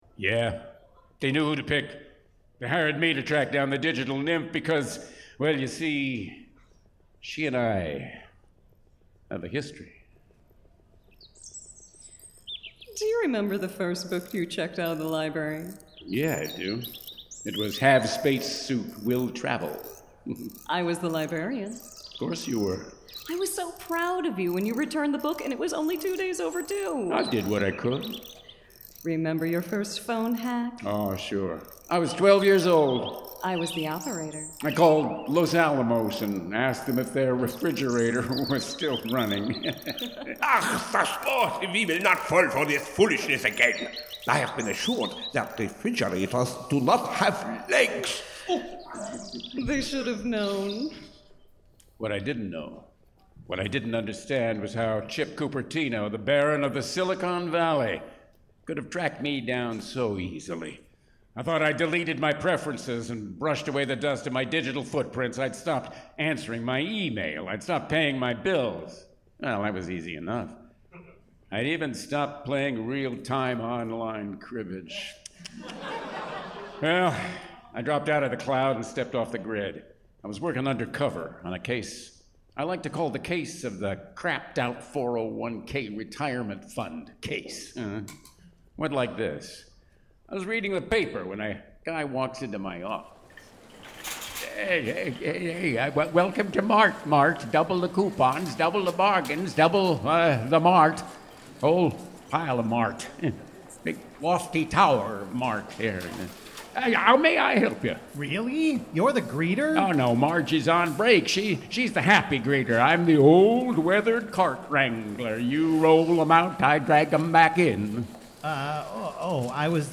Live Sound Effects